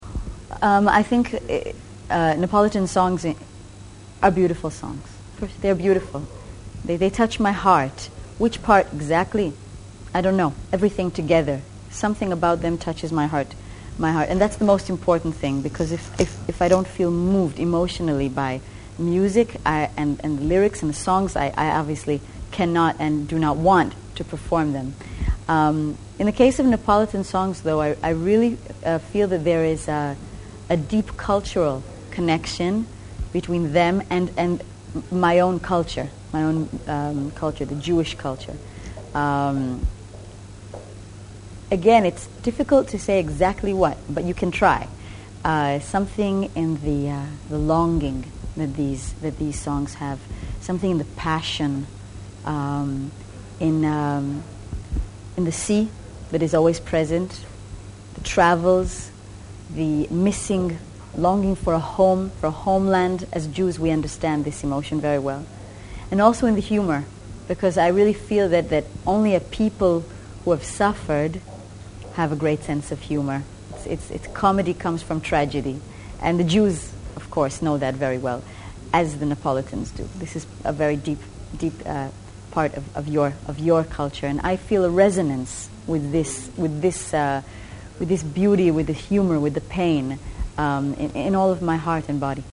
Abbiamo ritrovato nei nostri archivi un’intervista in cui Noa spiega bene il suo forte legame con Napoli.